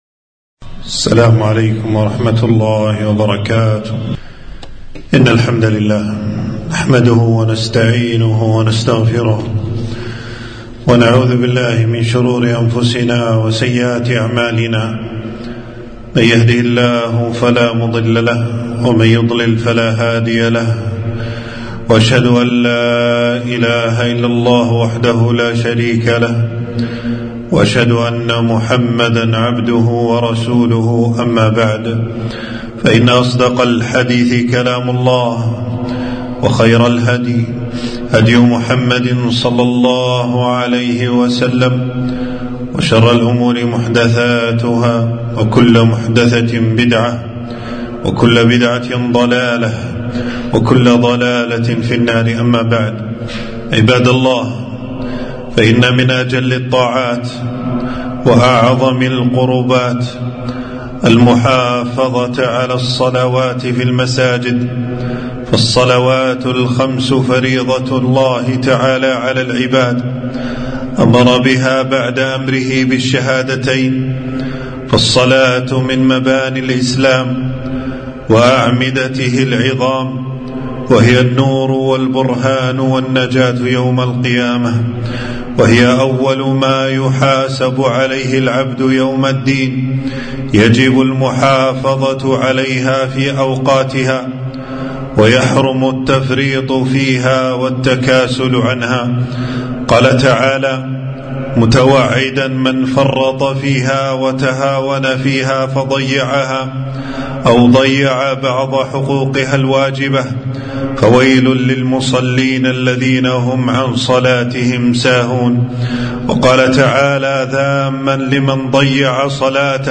خطبة - أهمية المحافظة على صلاة الفجر